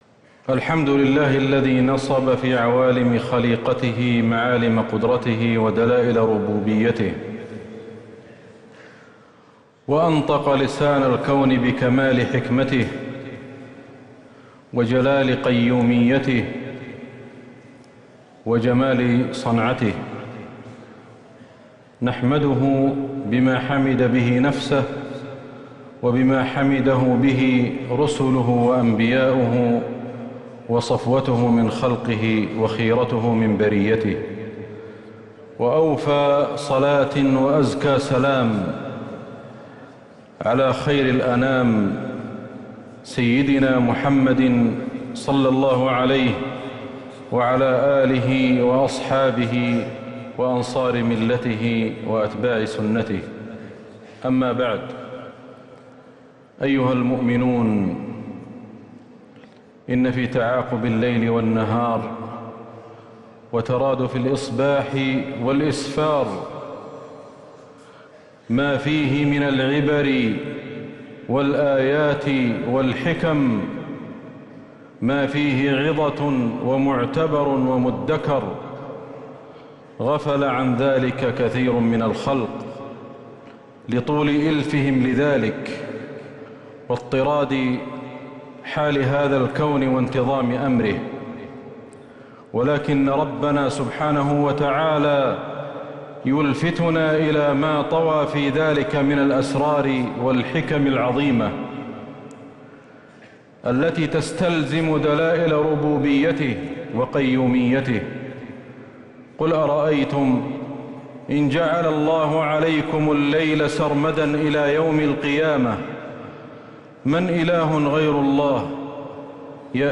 خطبة الكسوف الثلاثاء 29 ربيع الأول 1444هـ > الخطب > المزيد